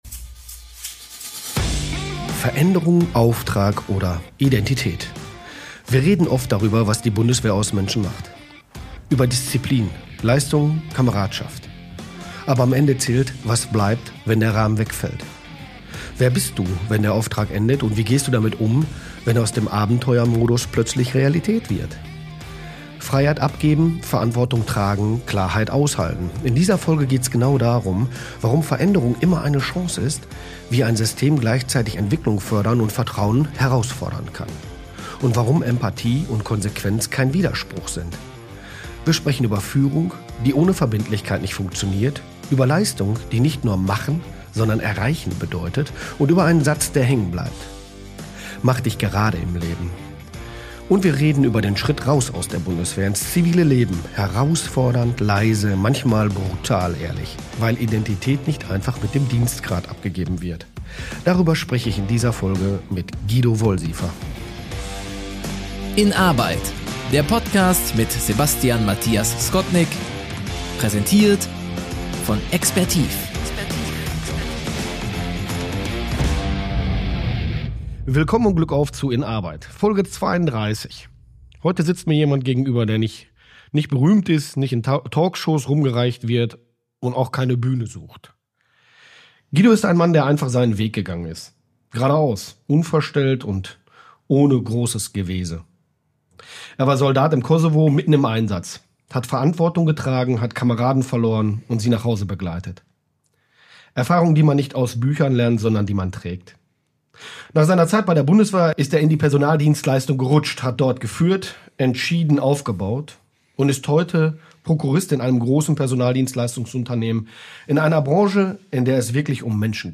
Ein Gespräch über den Moment, in dem aus Abenteuer Realität wird. Über Führung, die ohne Haltung nicht funktioniert.